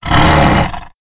c_sibtiger_hit3.wav